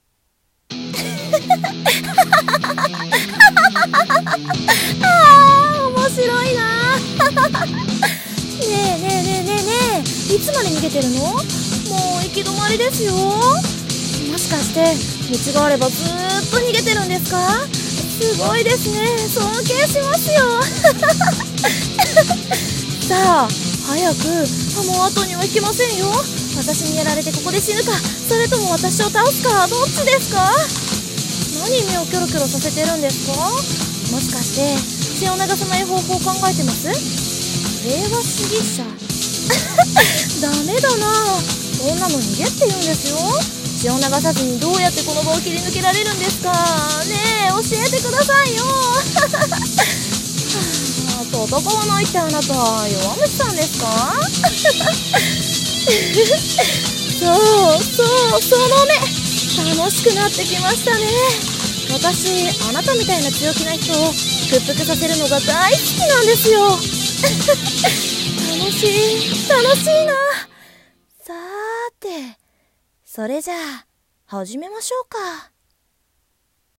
【悪役台本】行き止まり【一人声劇】